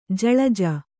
♪ jaḷaja